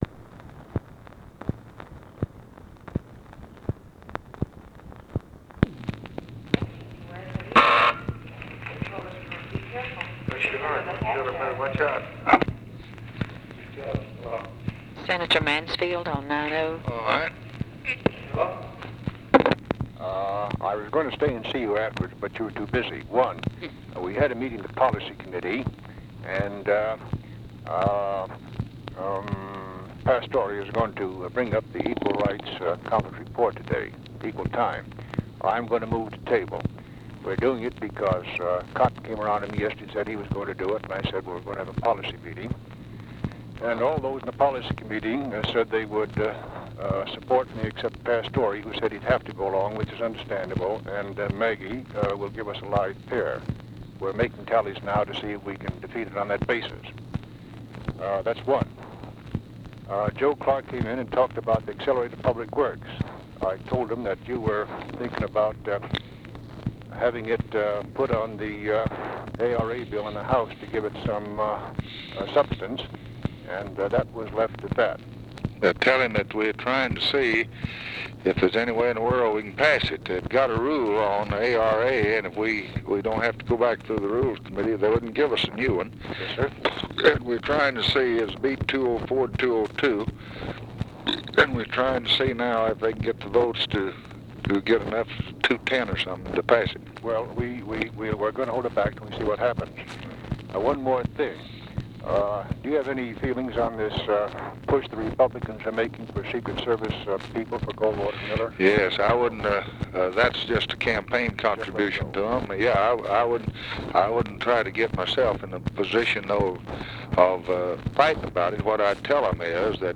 Conversation with MIKE MANSFIELD and OFFICE CONVERSATION, August 18, 1964
Secret White House Tapes